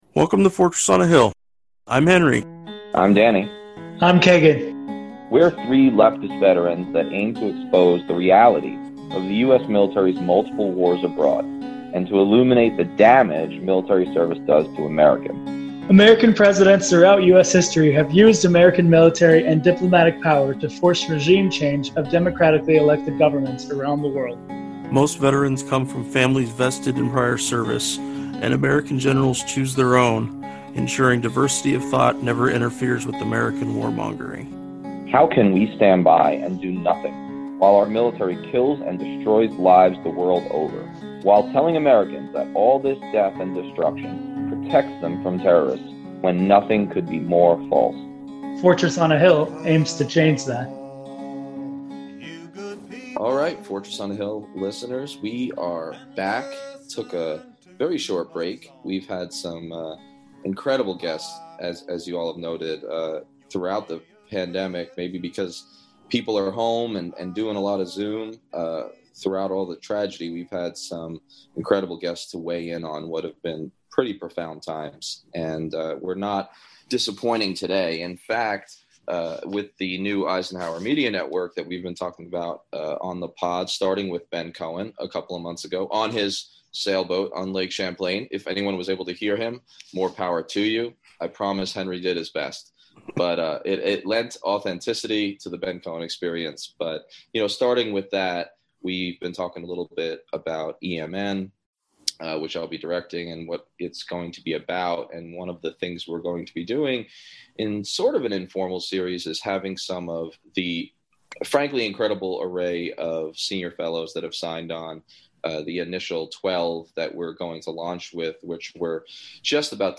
Coleen Rowley, retired FBI agent and whistleblower on the FBI’s inaction prior to and following September 11, 2001, joins us for a discussion on her career history to include her […]